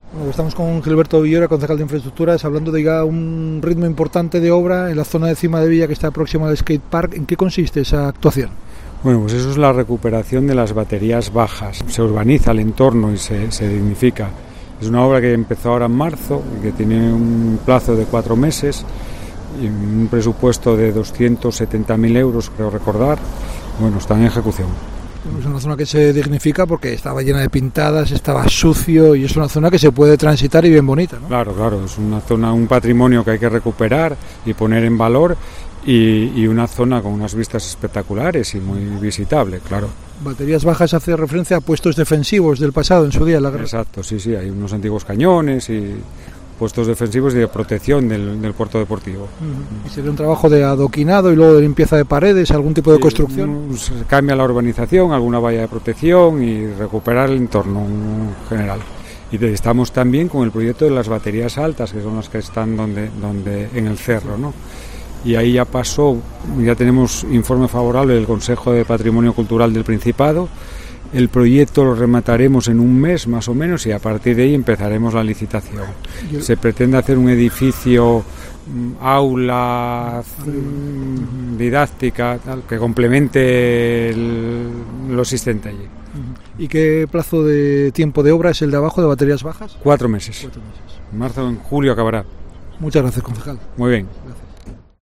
Gilberto Villoria, concejal de Infraestructuras, explica en COPE Gijón la características de la obra